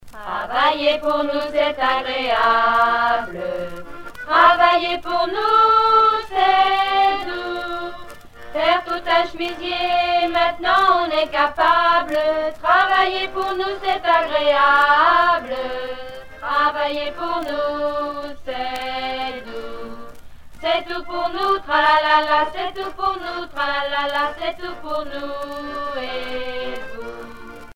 Catégorie Pièce musicale éditée